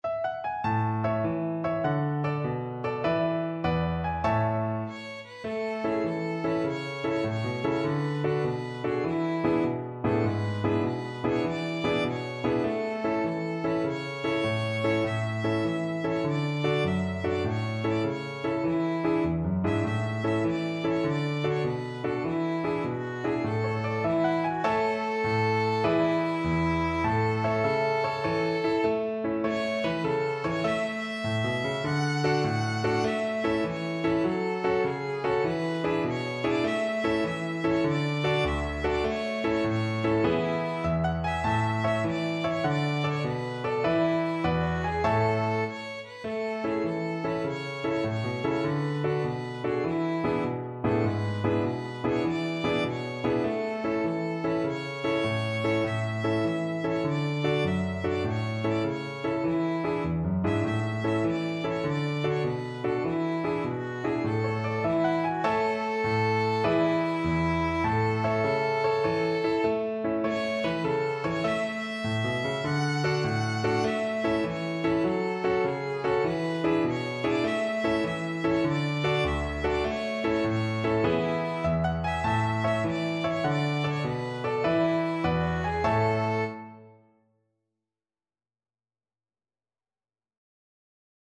Violin
A major (Sounding Pitch) (View more A major Music for Violin )
Allegro .=c.100 (View more music marked Allegro)
6/8 (View more 6/8 Music)
Traditional (View more Traditional Violin Music)